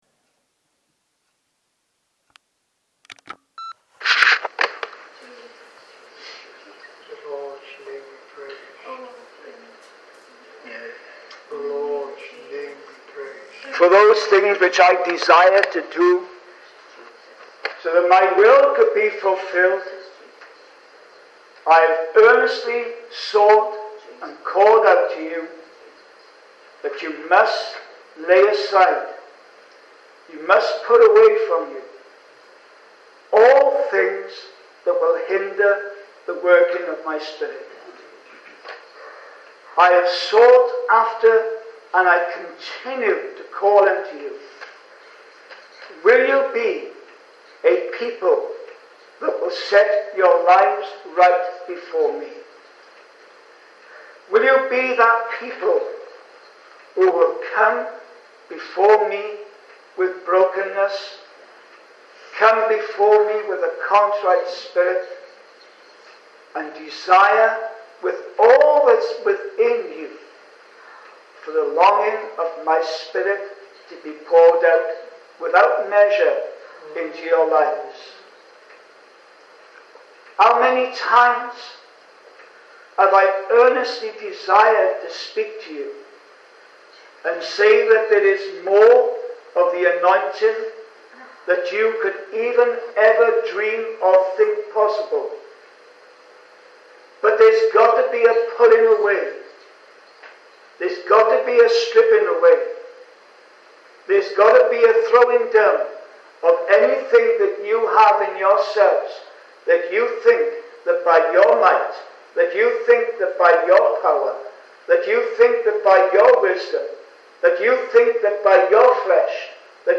Note: This article is transcribed from a real church recording .